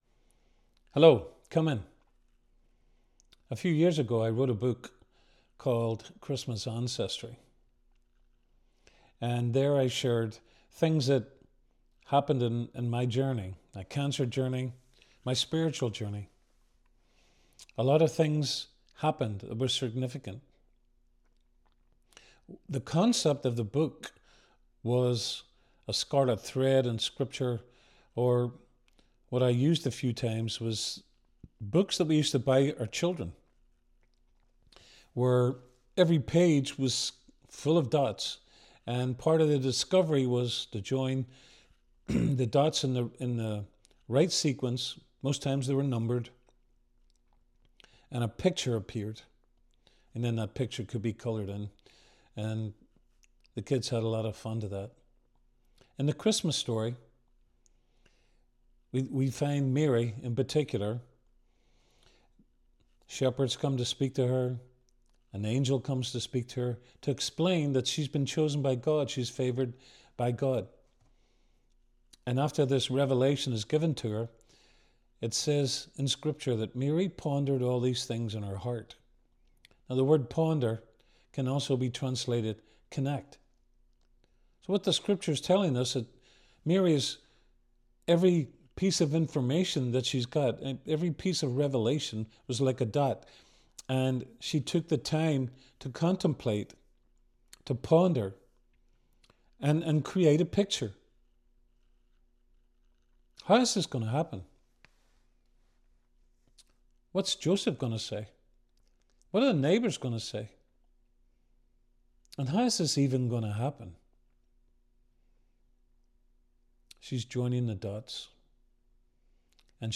A Christmas devotional